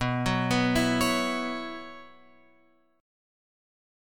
B Suspended 2nd Suspended 4th